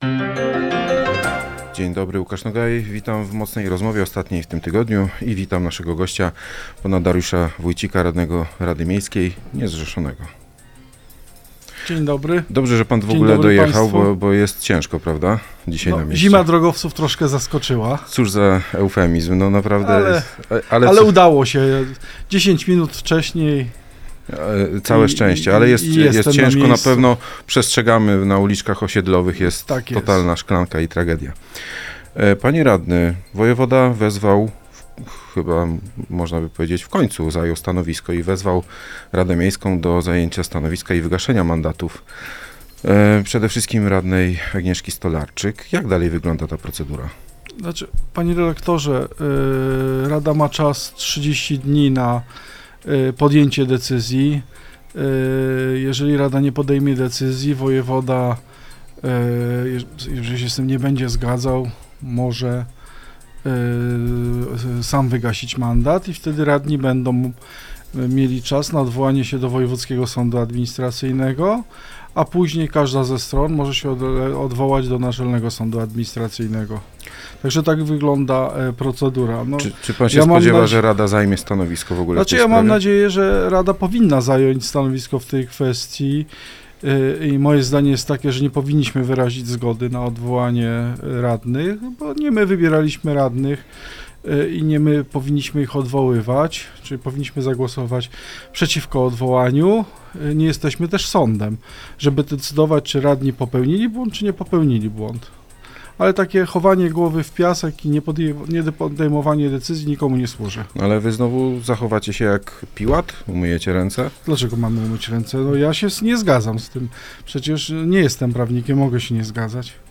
Dariusz Wójcik, radny niezrzeszony był gościem